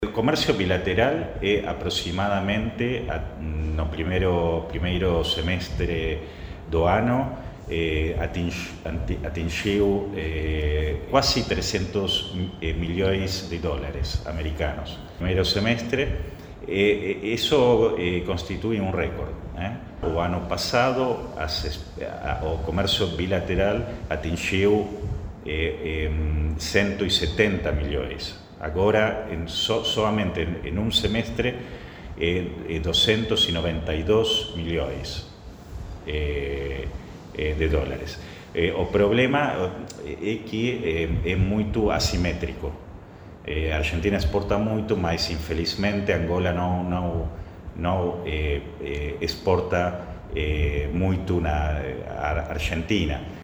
O Embaixador da Argentina em Angola, Nicolás Reboque diz, no entanto, que, neste valor, o seu país tem maior protagonismo, uma vez que exporta mais para Angola.